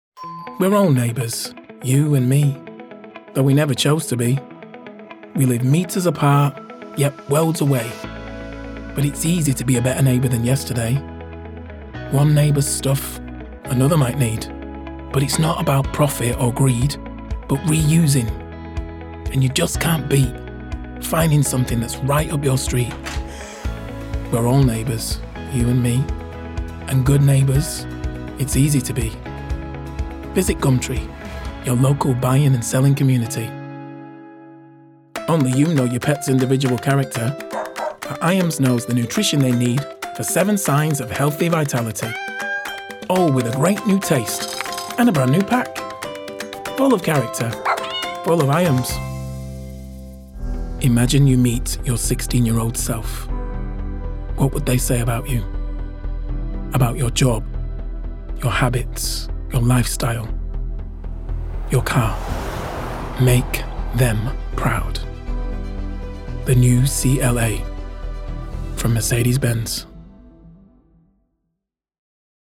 20s – 40s. Male. Manchester.